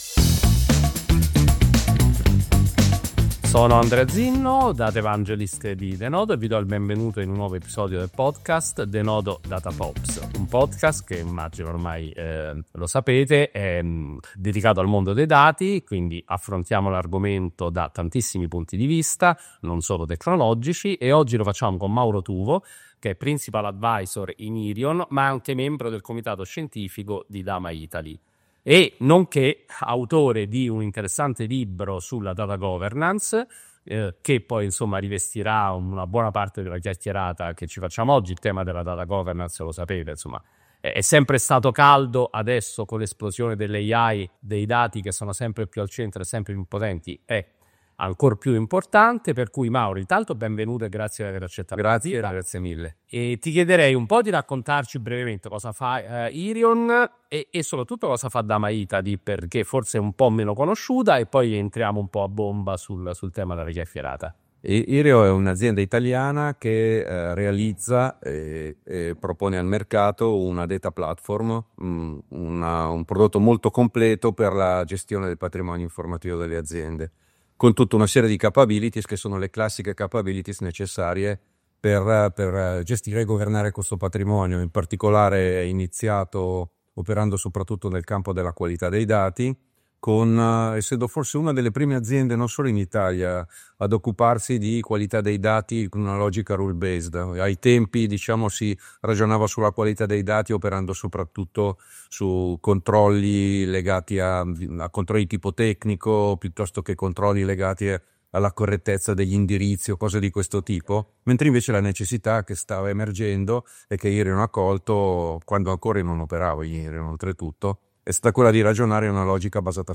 La Data Governance, da elemento accessorio a fulcro del Data Management-edited-01.mp3